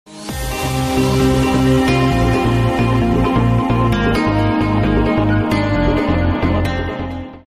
• Качество: 128, Stereo
гитара
громкие
без слов